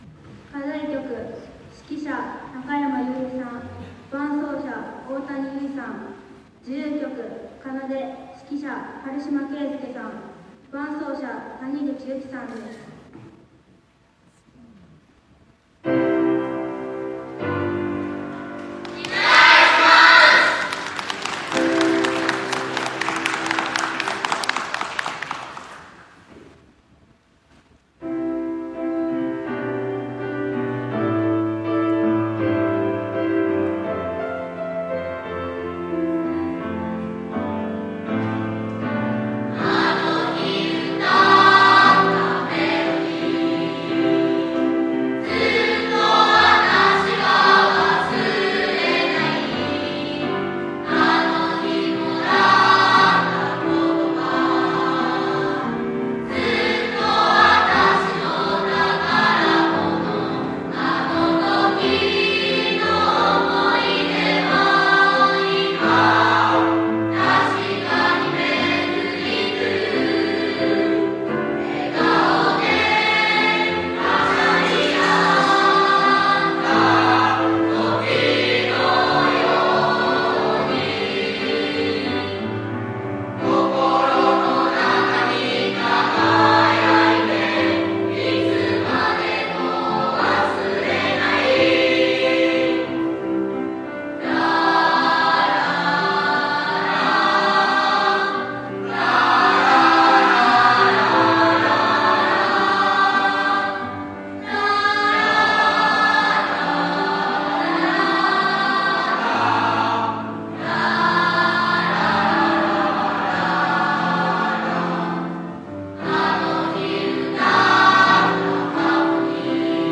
○先日行われた２年生の合唱コンクールでの各学級の音源を 掲載いたします。